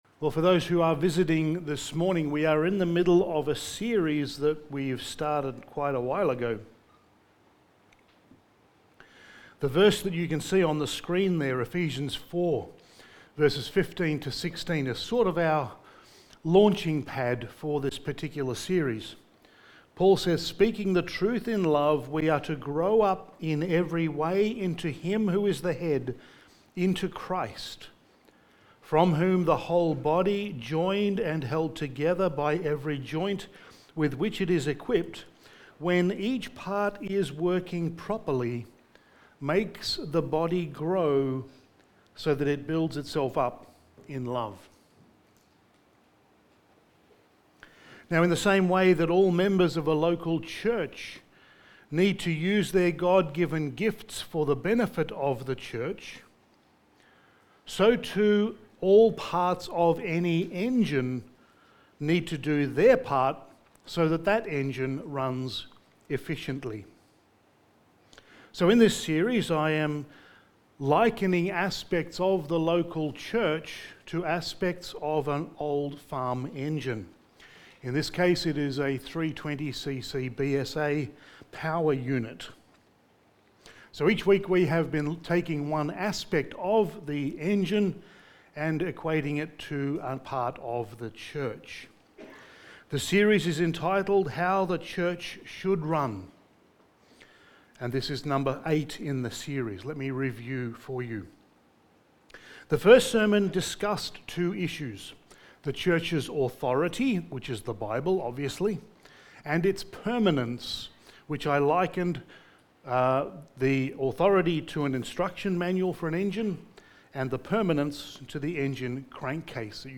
How the Church Should Run Series – Sermon 8: The Purposes of the Local Church
Service Type: Sunday Morning